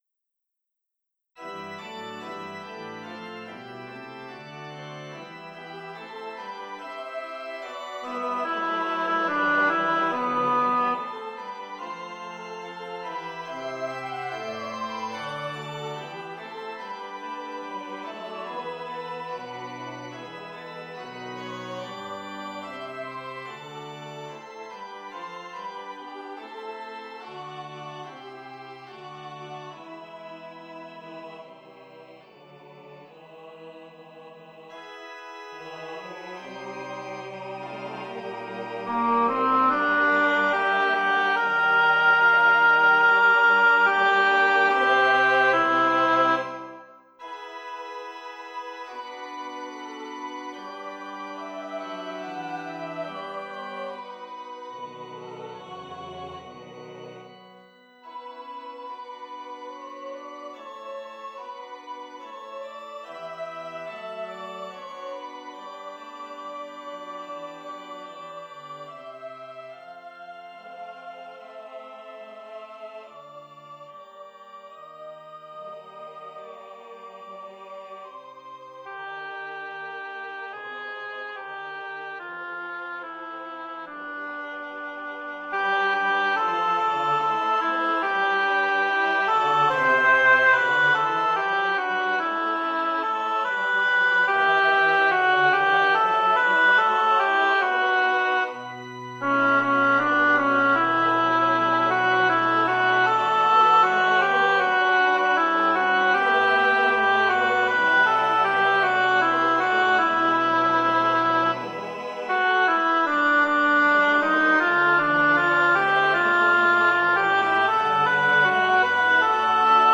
Playback with score pages in sync is generated by MuseScore.
Choir members can either watch and listen to MuseScore playing each voice part, or download the MP3 audio and MP4 video files for practice (right-click, then "Save link as...").
Magnificat and Nunc Dimittis
SATB with organ
Rootham_Op.90_Magnificat_alto.mp3